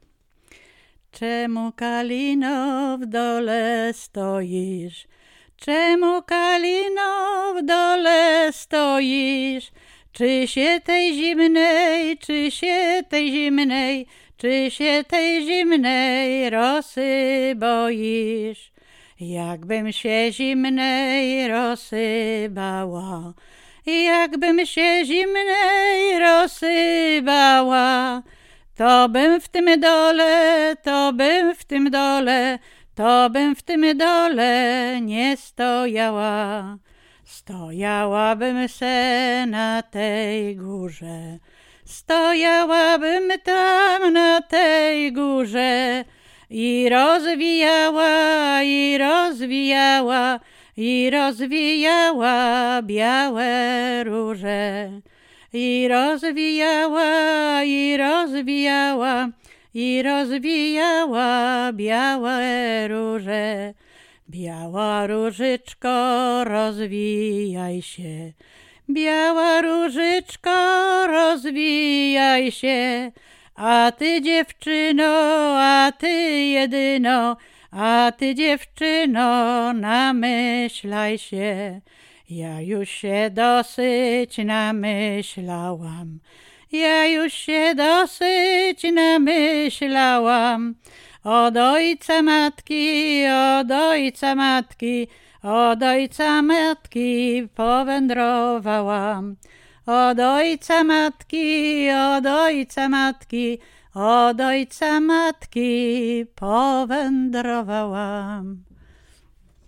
miłosne wesele weselne